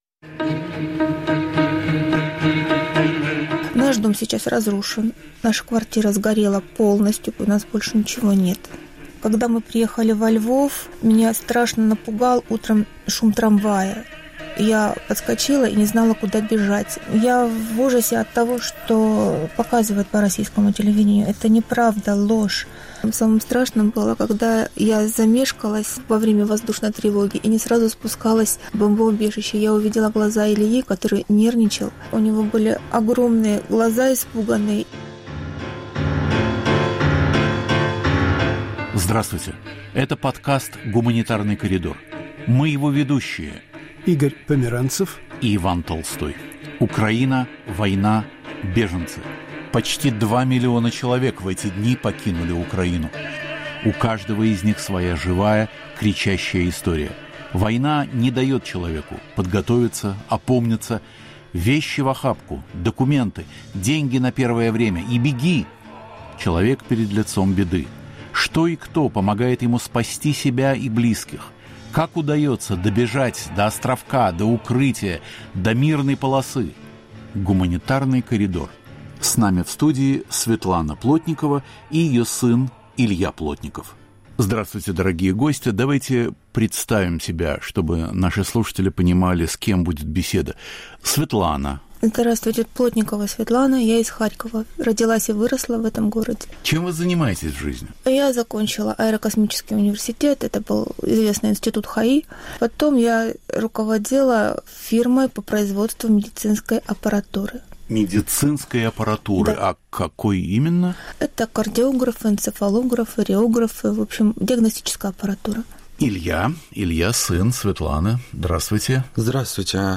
В студии "Свободы"